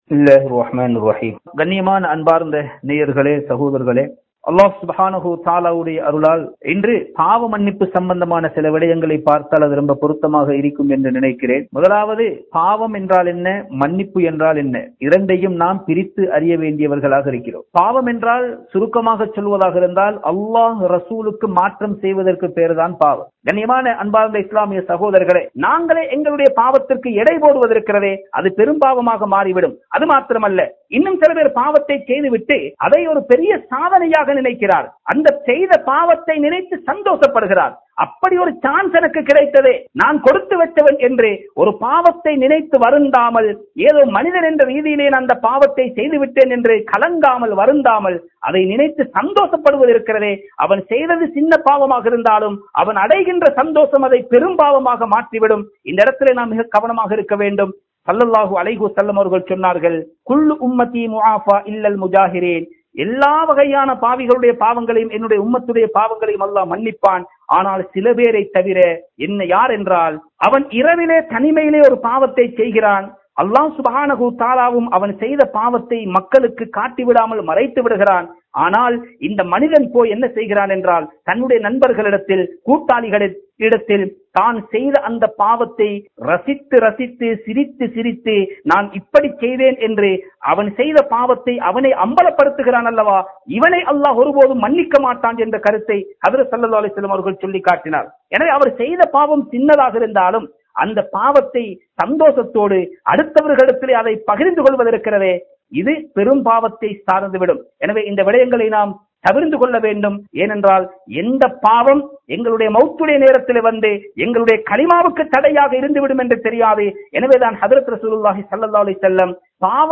Bayans